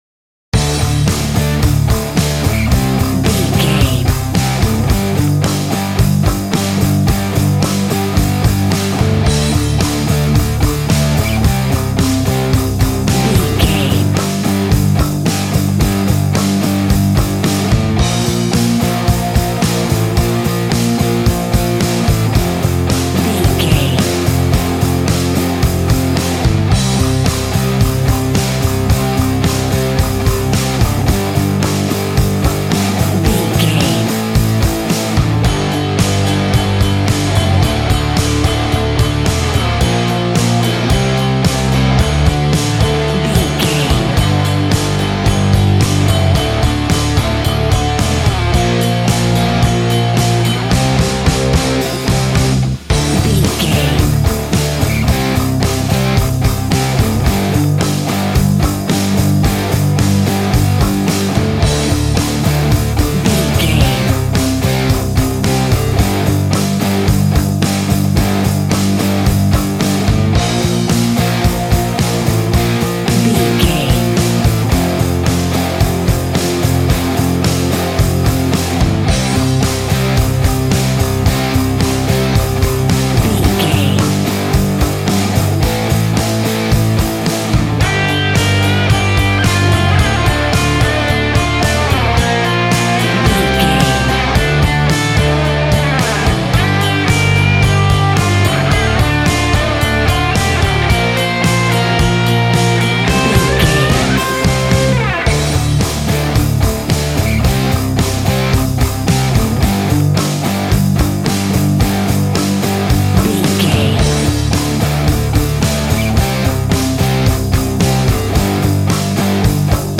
Aeolian/Minor
hard
groovy
powerful
electric guitar
bass guitar
drums
organ